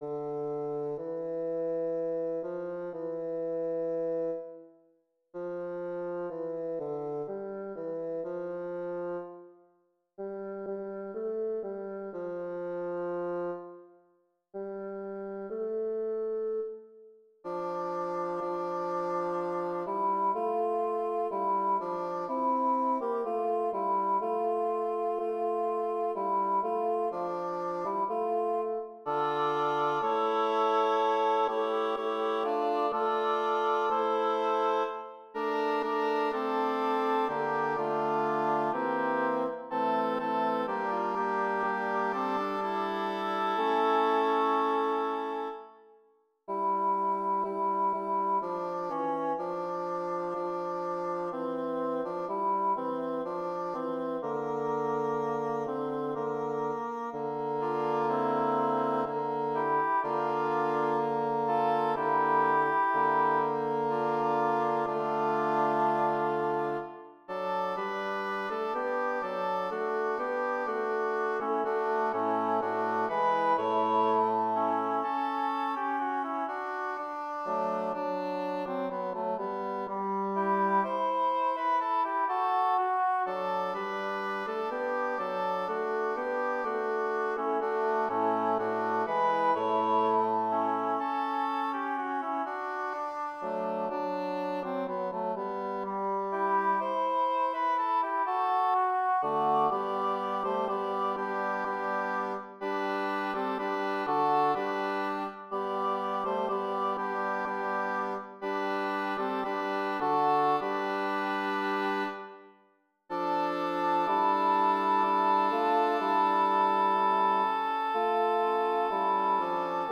Voicing/Instrumentation: SATB
His music blends early music, 20th-century elements, and fundamentalist musical traditions